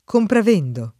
[ komprav % ndere ]